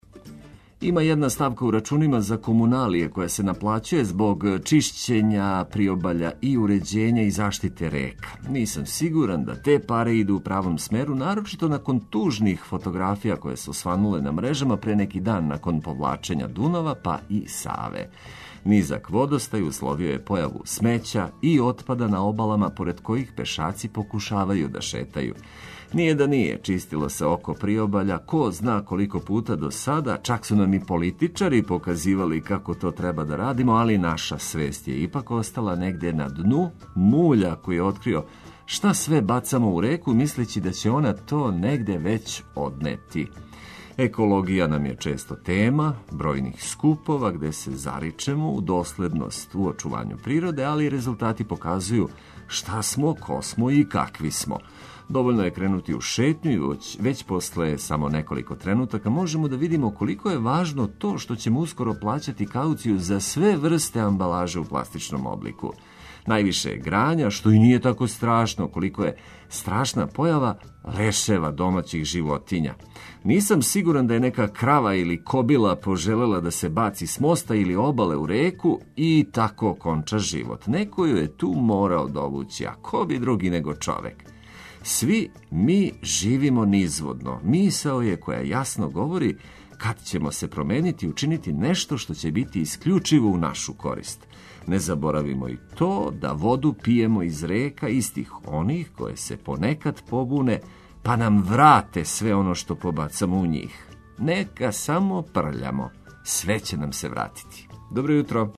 Са нама ни јутрос неће бити стресно.